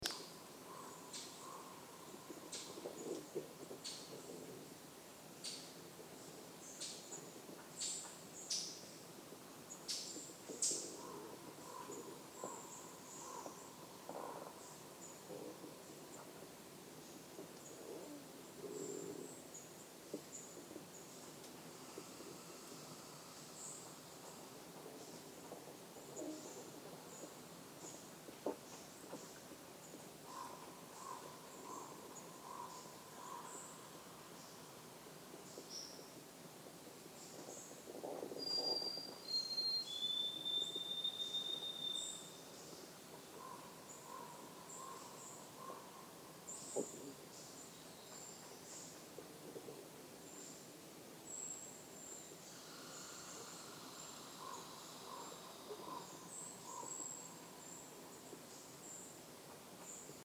A winter wren sings down by the water, another one of my favorites, while a black-capped chickadee sings near my campsite.
Several chatter repeatedly, as if they are protesting my presence.